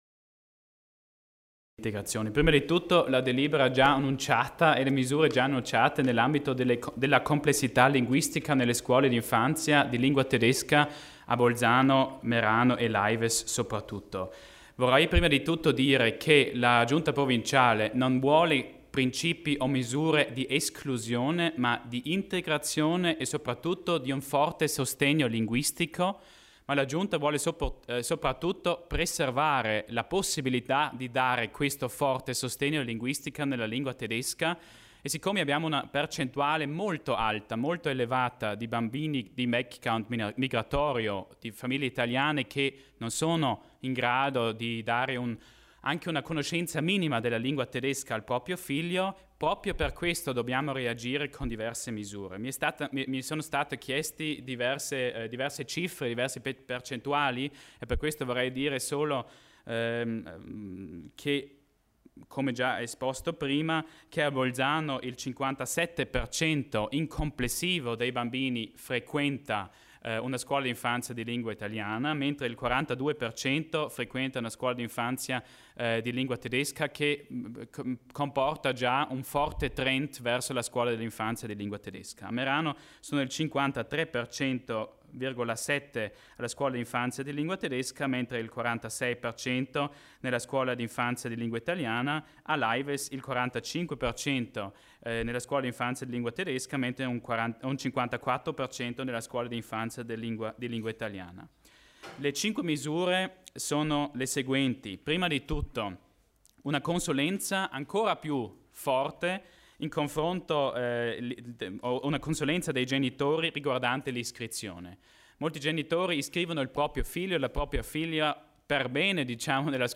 L'Assessore Achammer illustra i provvedimenti che interessano le scuole materne in lingua tedesca